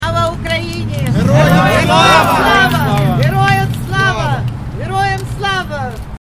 0301-Crowd-chants.mp3